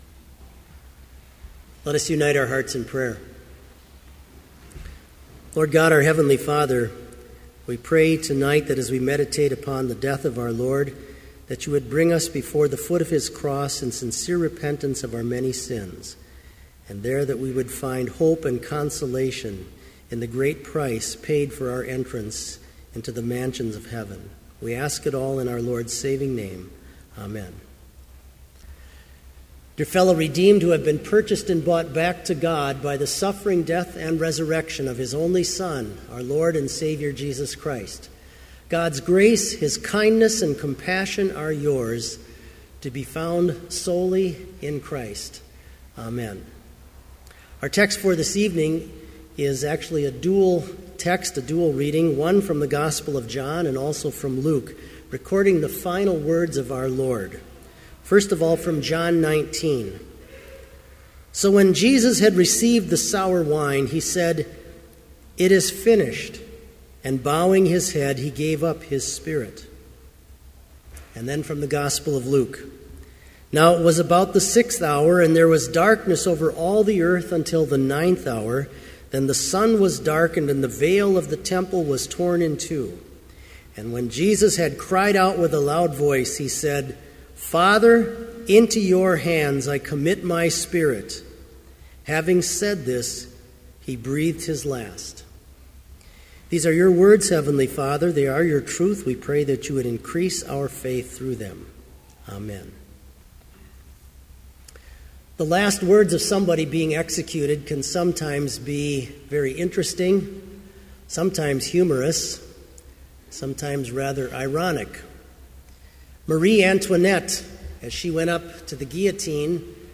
Sermon audio for Lenten Vespers - March 25, 2015